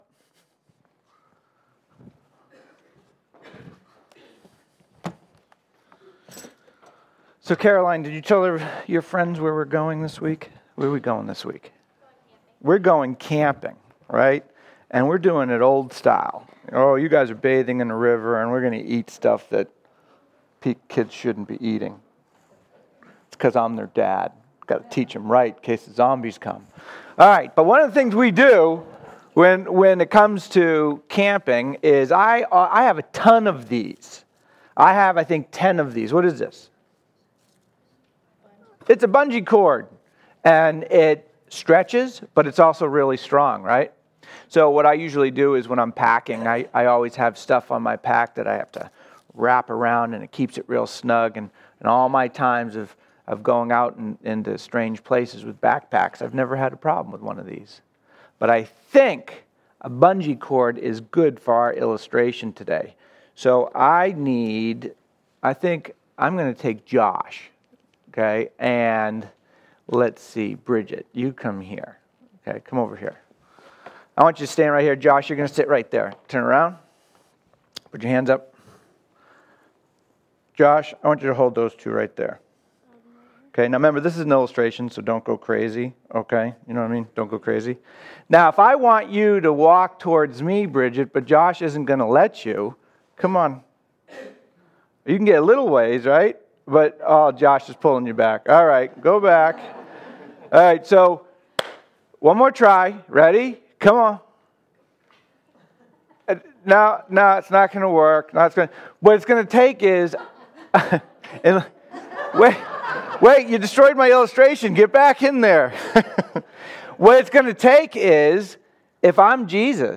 Safe Harbor Community Church Sermons